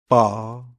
Ääntäminen
IPA: /ˈbaːɐ/